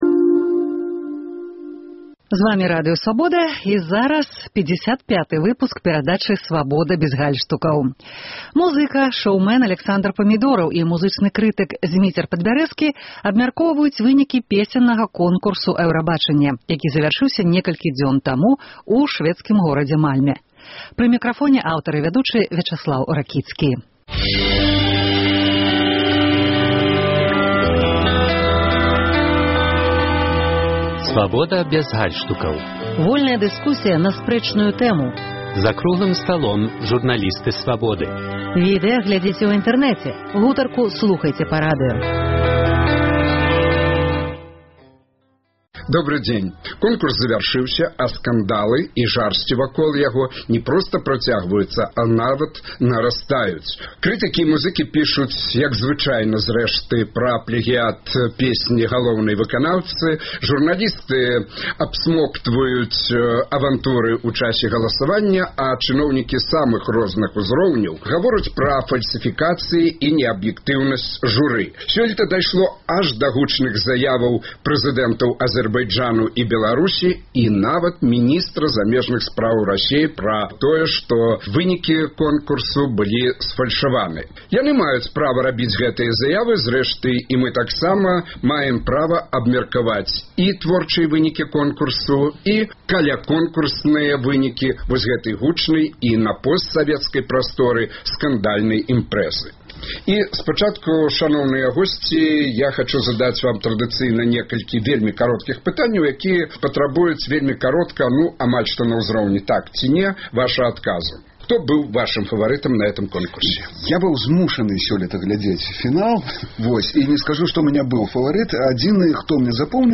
Вядоўца